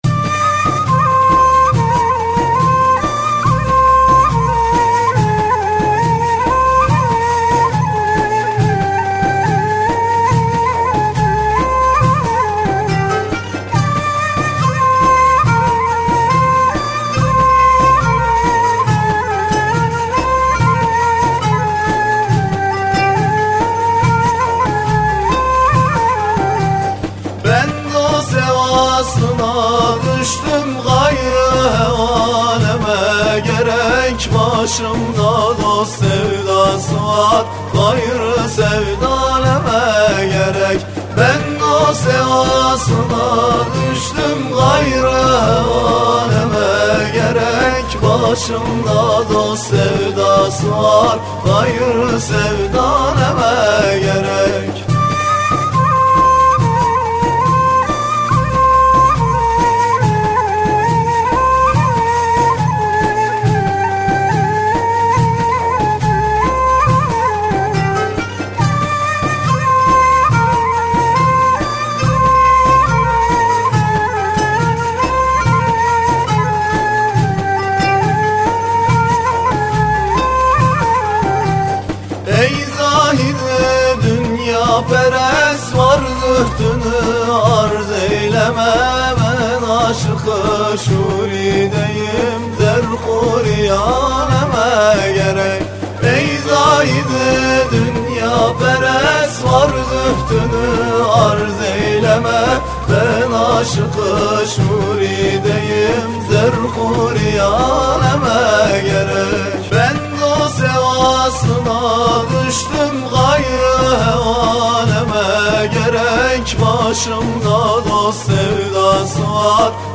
Etiketler: şanlıurfa, Tasavvuf